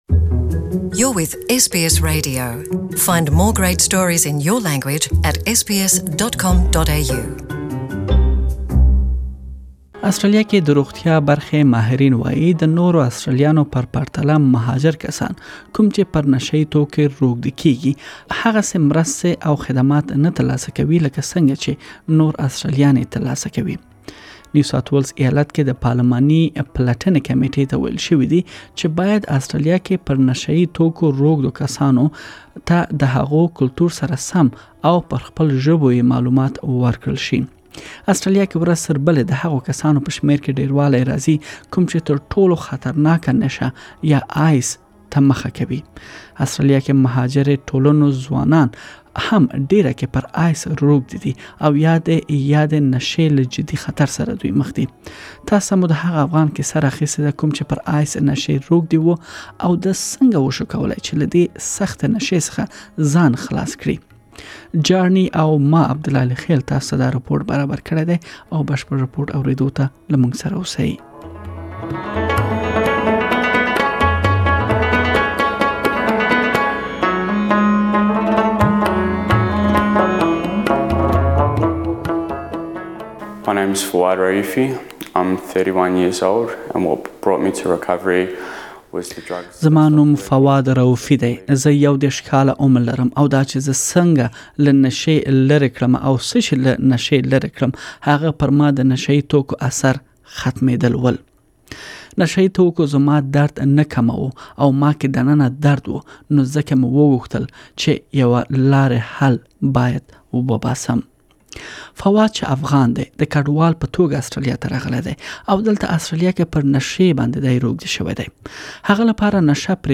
Please listen to the full report in Pashto.